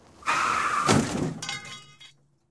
9_zvuk avarii machiny.ogg